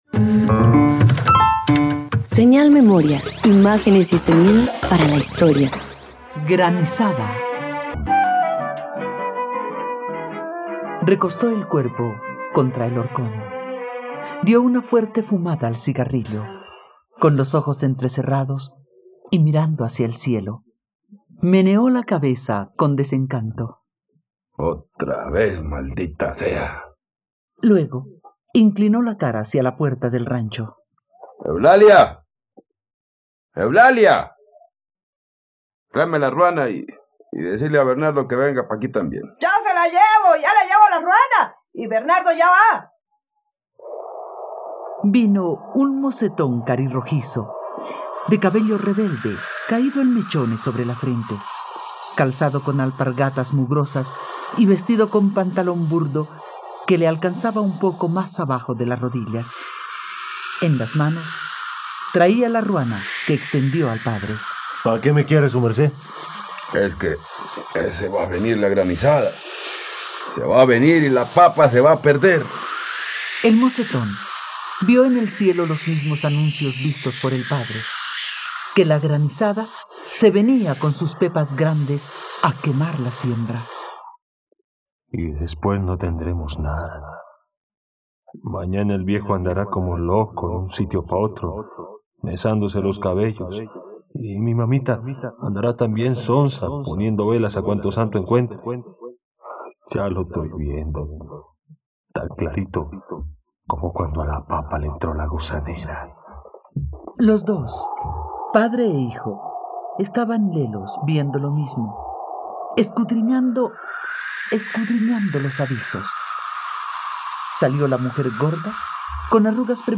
Granizada - Radioteatro dominical | RTVCPlay